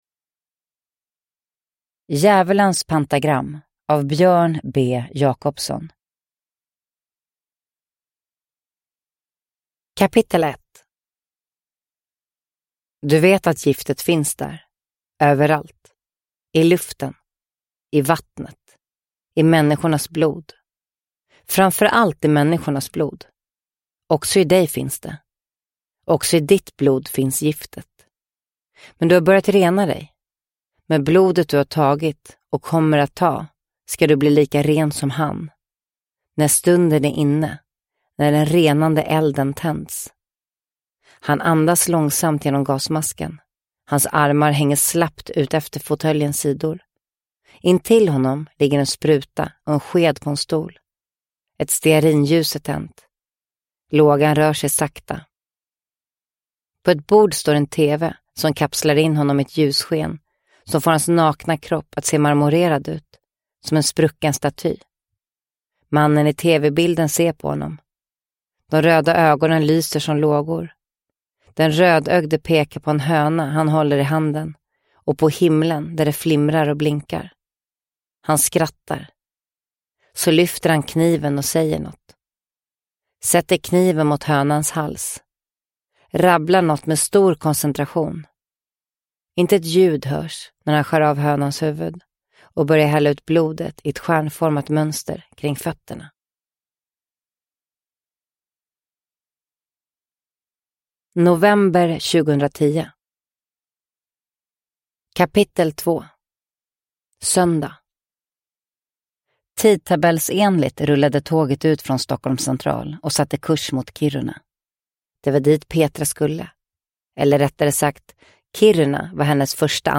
Djävulens pentagram – Ljudbok – Laddas ner
Uppläsare: Sanna Bråding